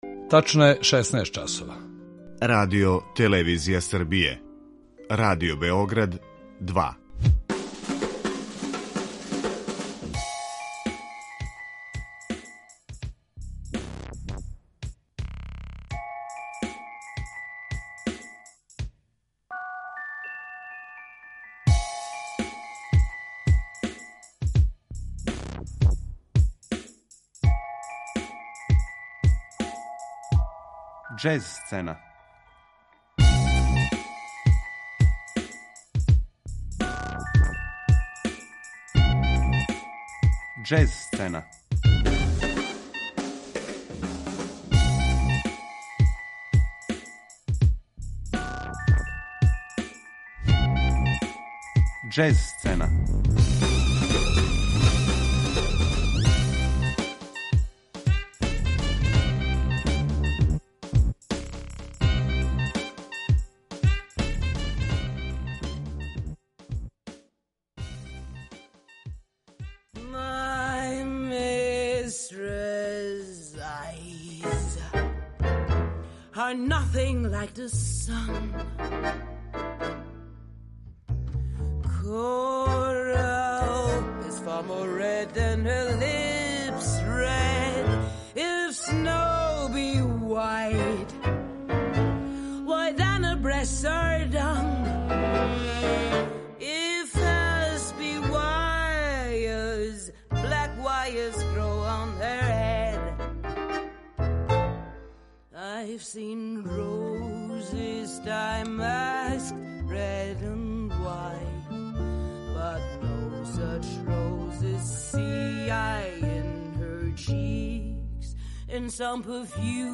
Два албума ауторски потписују немачки уметници, а два су снимљена на концертима у Берлинској филхармонији.